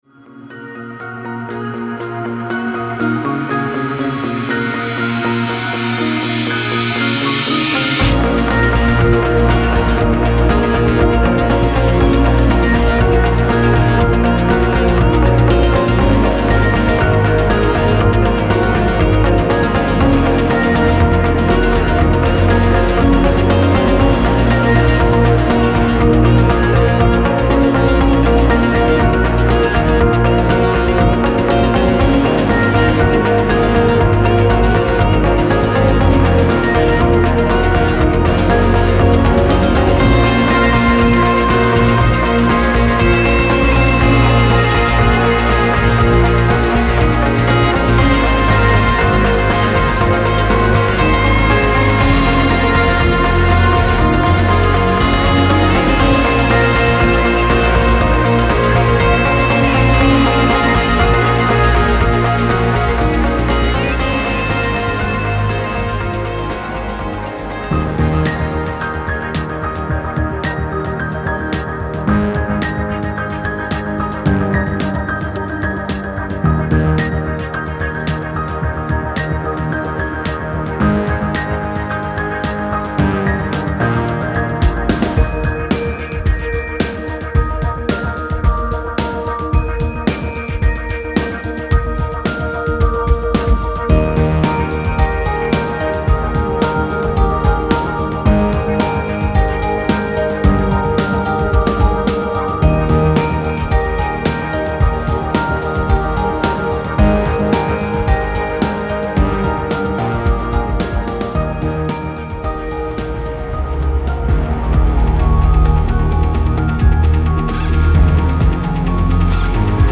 file under: Progressive Rock, Soundtrack